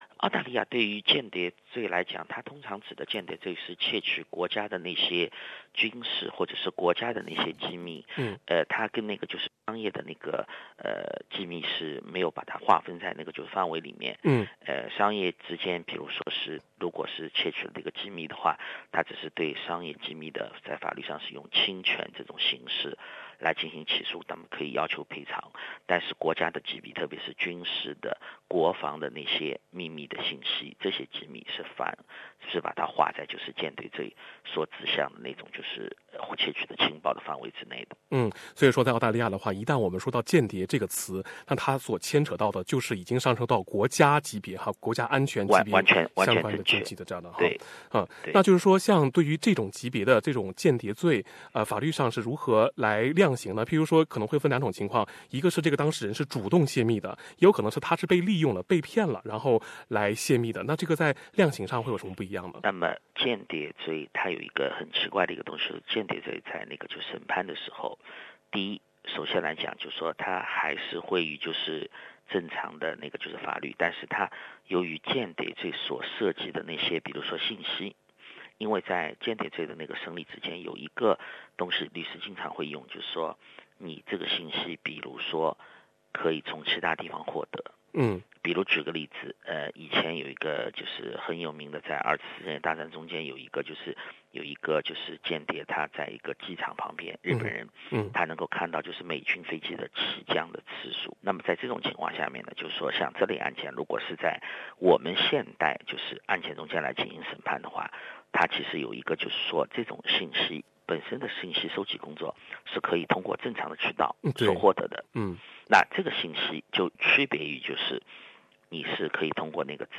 你需要了解的澳洲间谍法 08:53 Digital Era SBS 普通话电台 View Podcast Series Follow and Subscribe Apple Podcasts YouTube Spotify Download (4.07MB) Download the SBS Audio app Available on iOS and Android 如何避免让自己一不小心就被当作间谍？